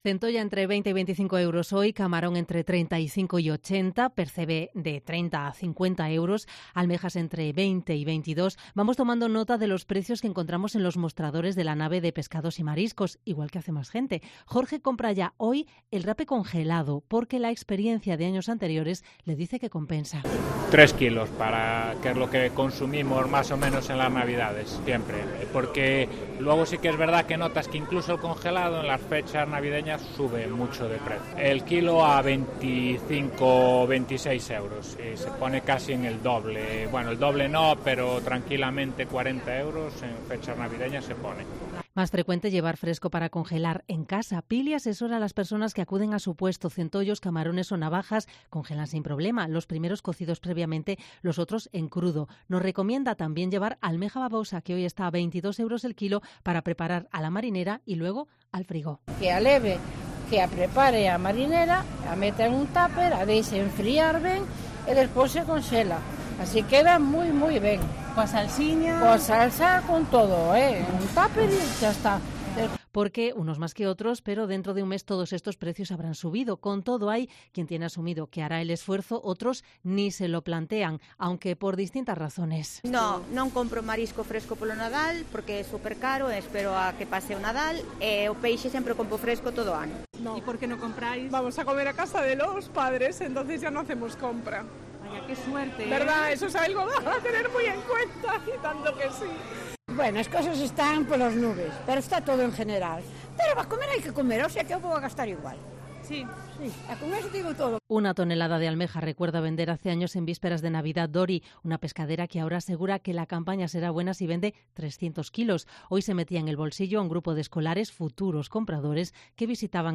Comprobamos precios en la plaza de Abastos de Santiago a un mes para la Nochebuena